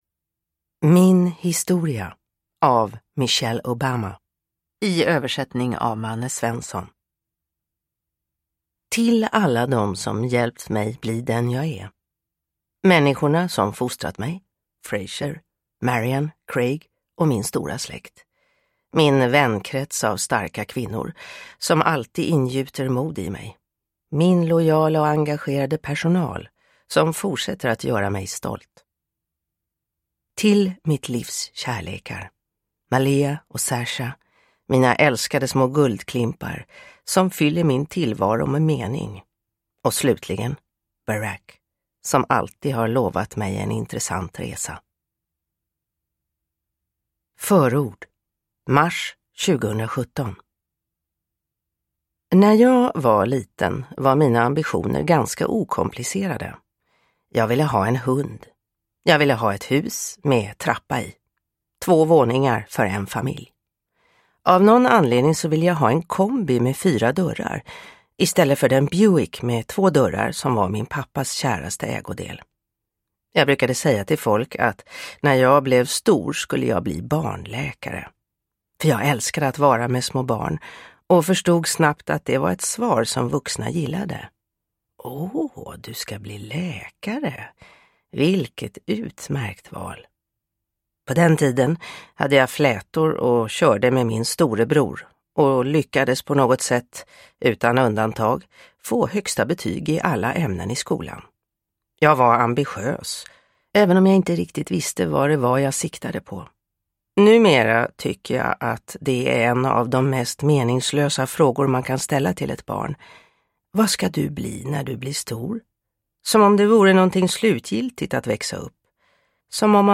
Min historia – Ljudbok – Laddas ner